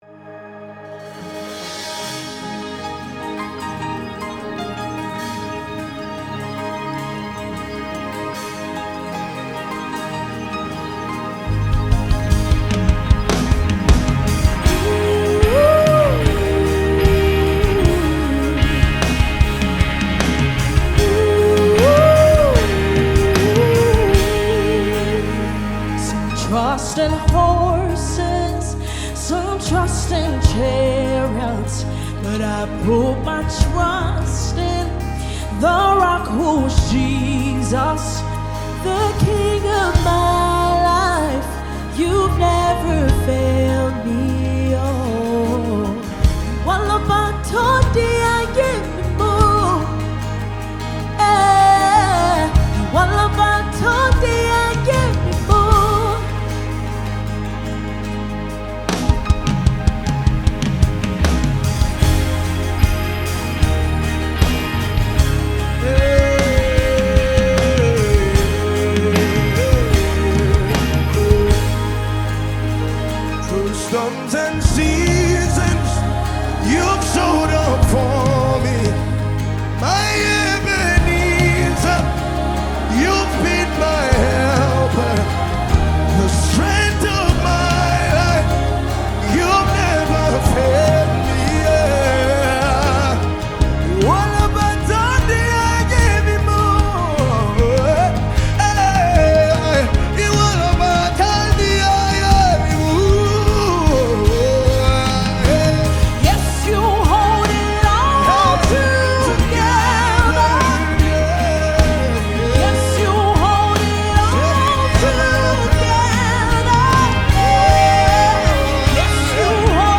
Yoruba Fuji track
Nigerian Yoruba Christian song
Fuji Music